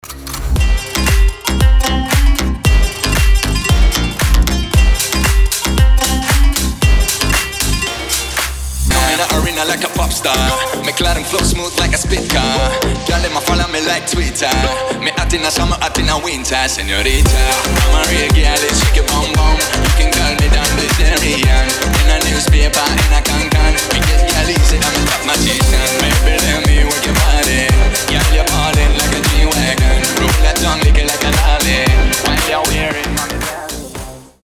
• Качество: 320, Stereo
Club House
басы
восточные
быстрые
ремиксы
Зажигательная клубная музыка